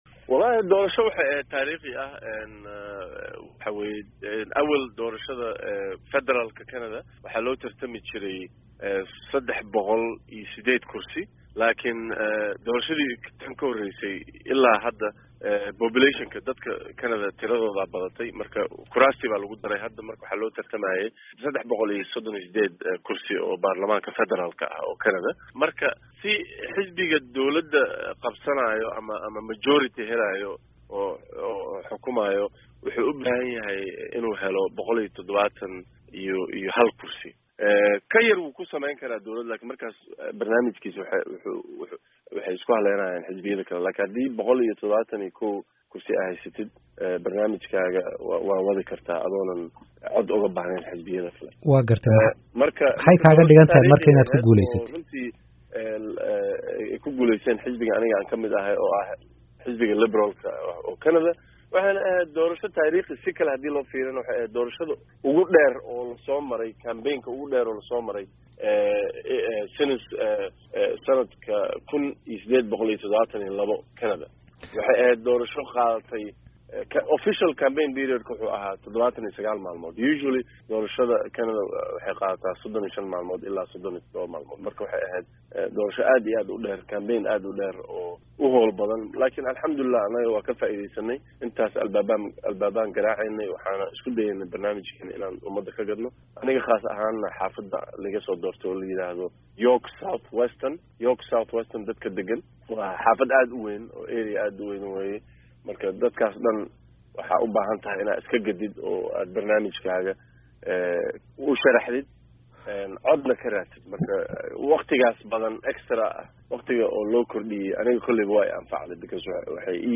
Dhegayso: Waraysiga Axmed Xuseen oo ah Xildhibaan loo soo dortay Baarlamaanka Canada